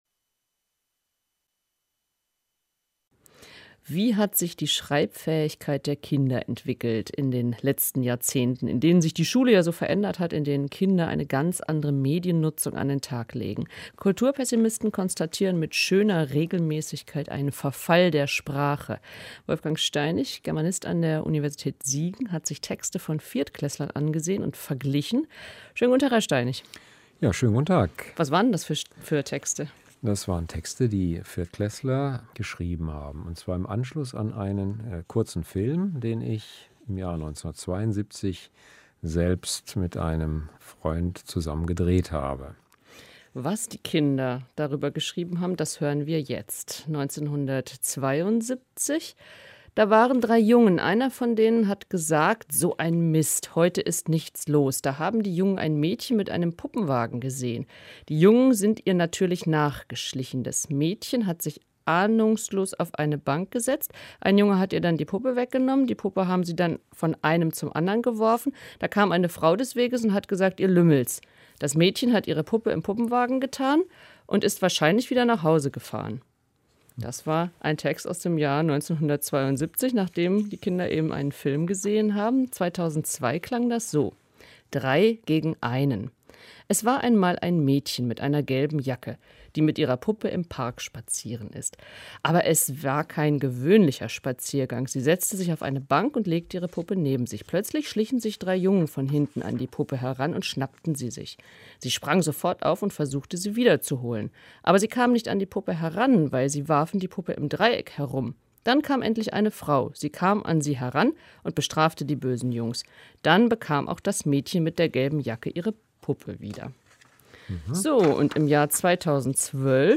dra-audio) - Eine Analyse von Schulaufsätzen von Viertklässlern aus vier Jahrzehnten zeigt den Mut zu mehr Eigensinn. Gespräch